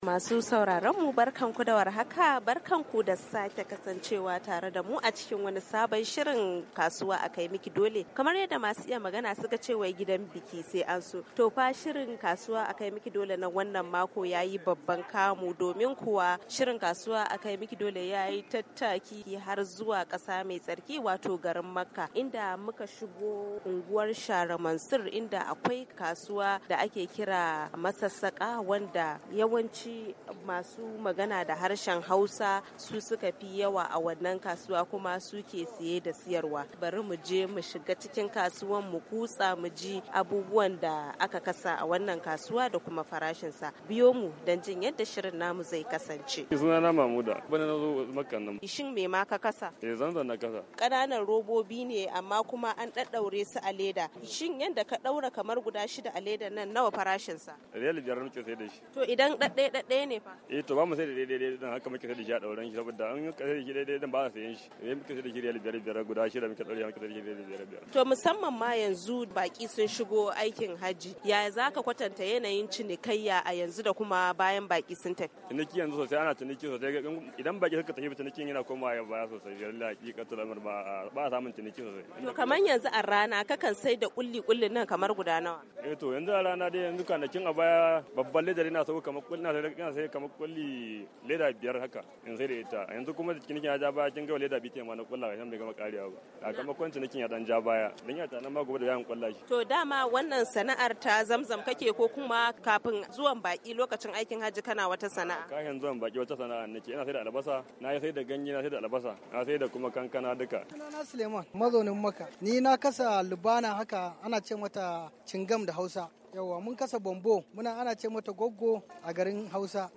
ABUJA, NIGERIA - A cikin shirin Kasuwa na wannan mako mun leka kasuwar Masassaka dake Unguwar Shahra Mansur a Makkah wanda yawancin masu saye da sayarwa a kasuwar masu magana ne da harshen Hausa.